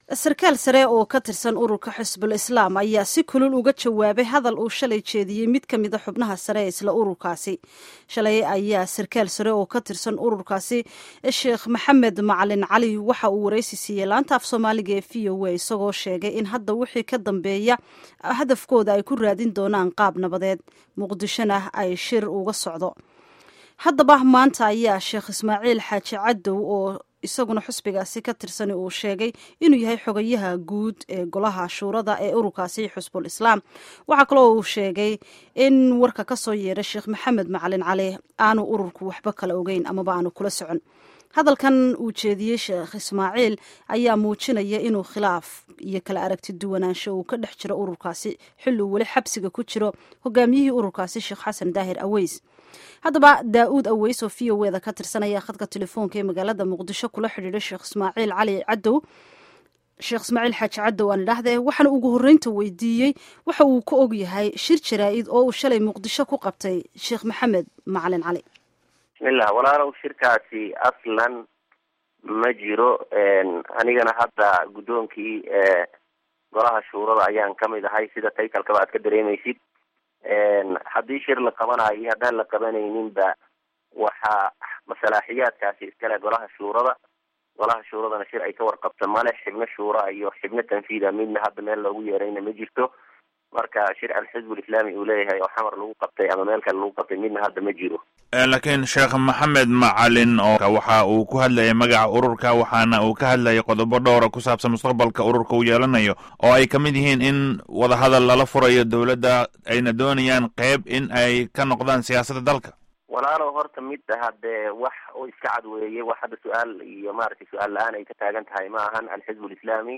Wareysi Xisbul Islam